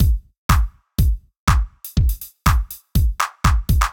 ORG Beat - Mix 3.wav